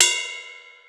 Crashes & Cymbals
RIDE_CUP1.WAV